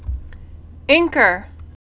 (ĭngkər)